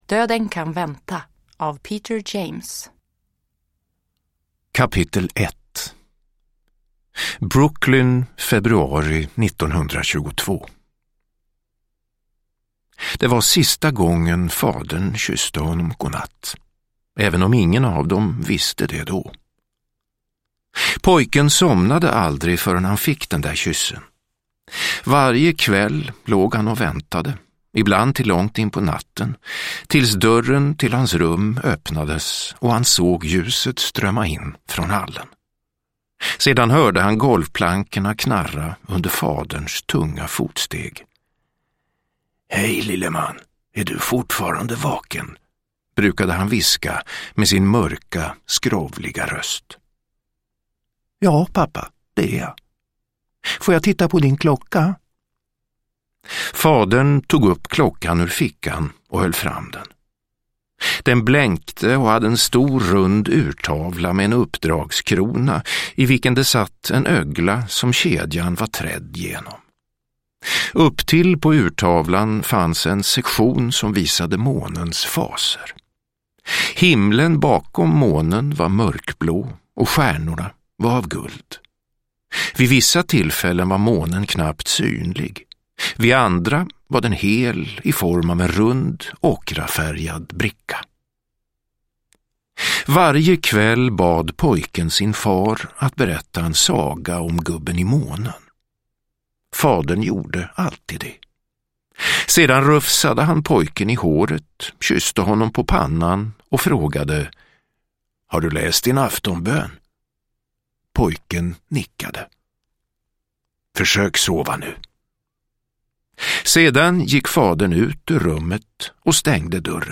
Döden kan vänta – Ljudbok – Laddas ner